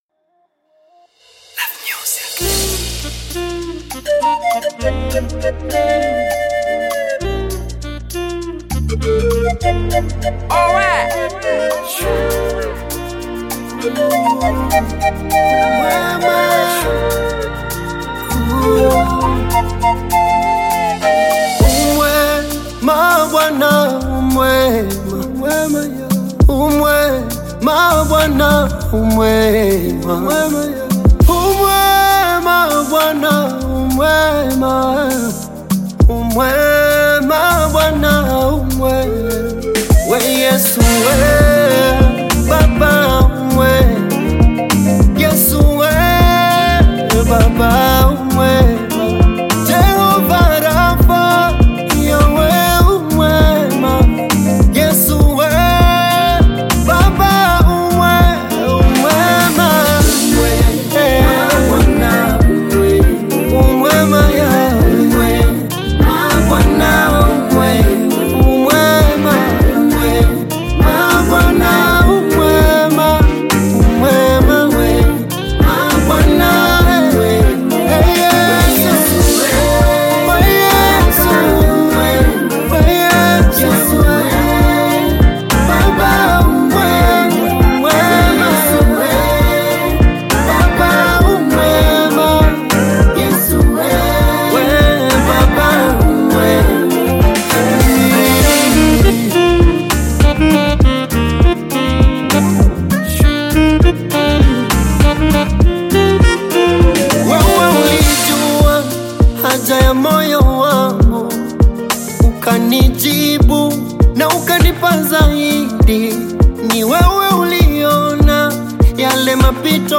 Gospel singer and songwriter
gospel song